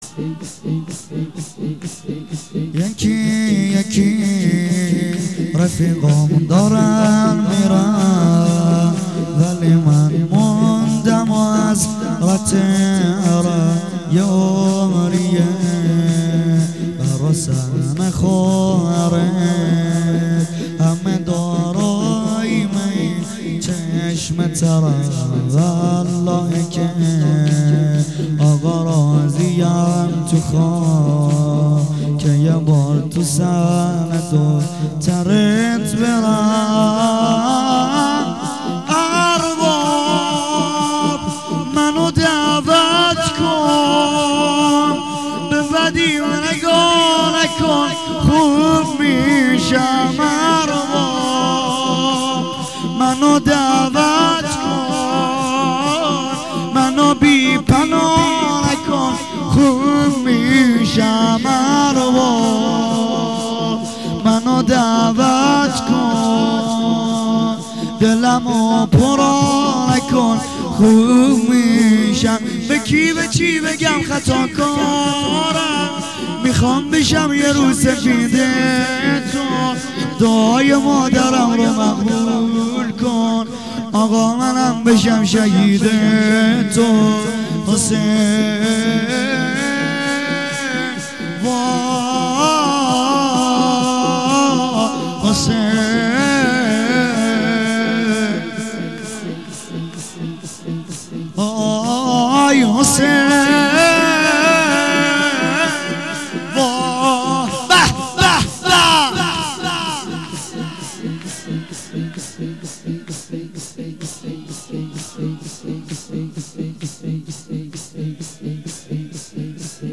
یکی یکی رفیقام دارن میرن شور احساسی